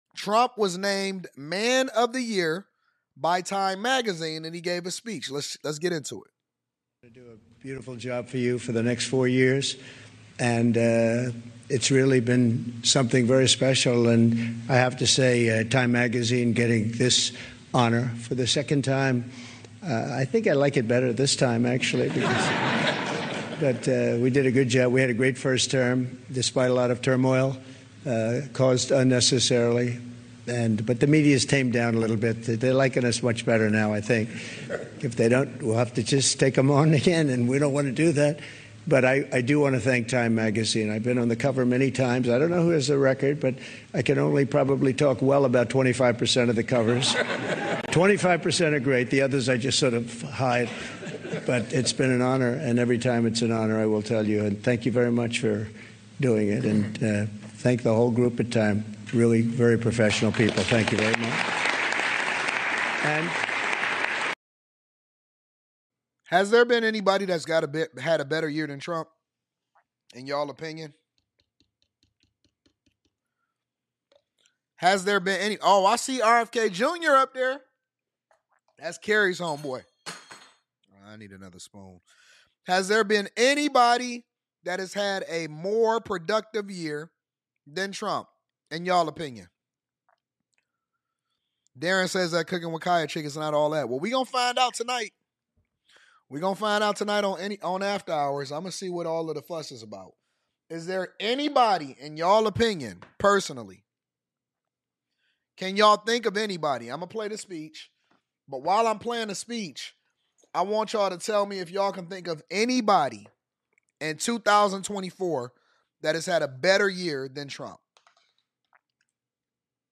Time Magazine named Trump Man of the Year, and he shared his achievements and future plans in a speech. He talked about speeding up big investments and thanked Time for the honor. Trump also stressed the importance of being careful with nuclear weapons and staying informed about politics, encouraging hard work and vigilance in life.